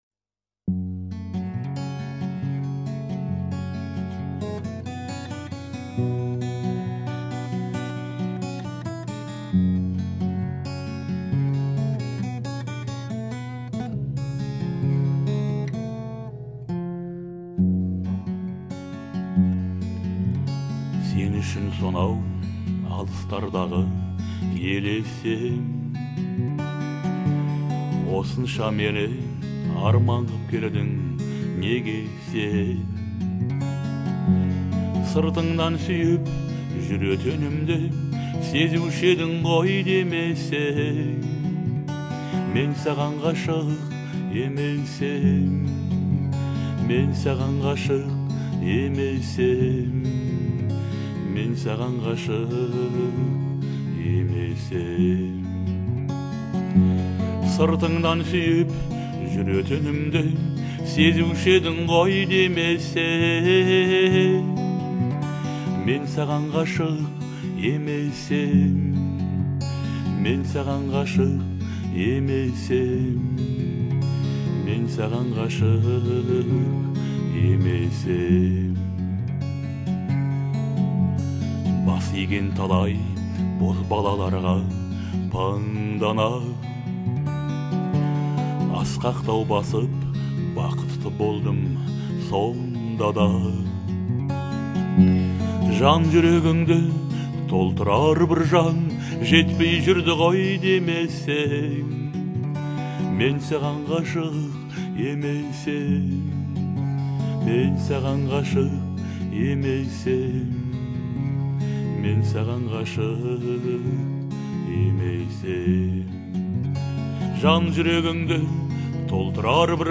это трогательная баллада